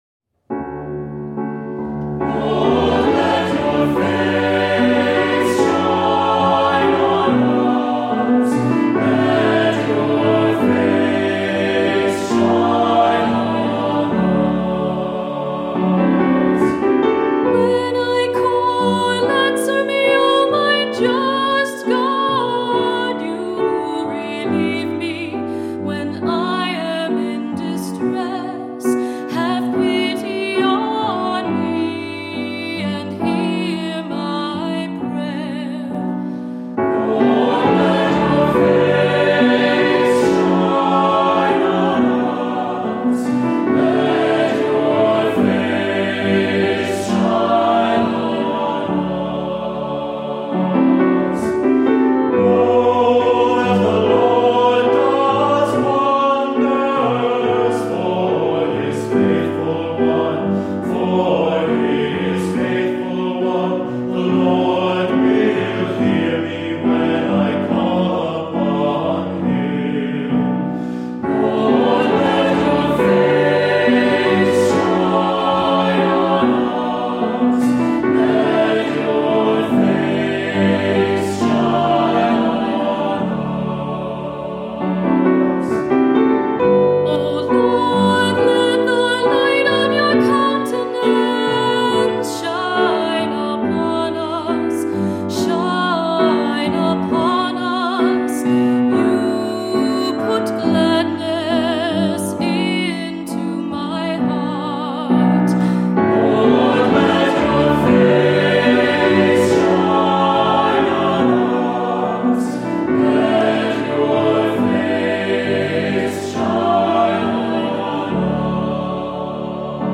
Voicing: "SATB", "Cantor"